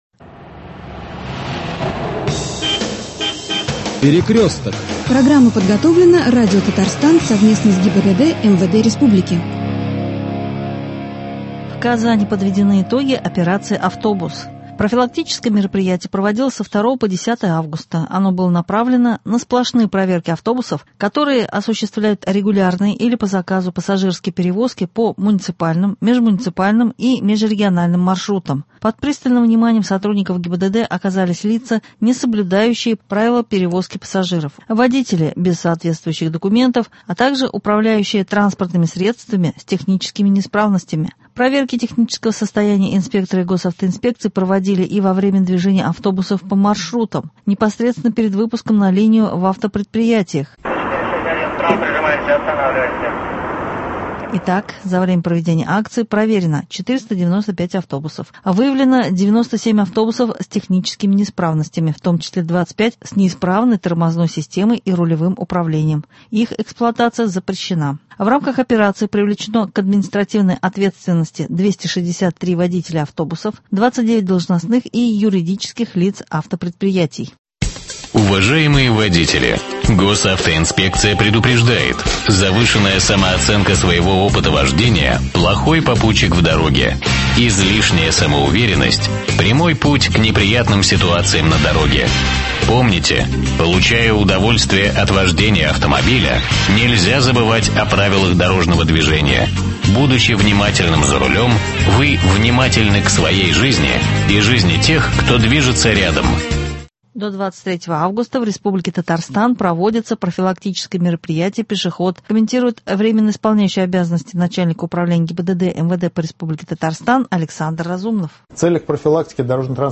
С 23 августа в Республике Татарстан проходит профилактическое мероприятие «Пешеход». Комментарий врио начальника УГИБДД МВД по РТ Александра Разумнова.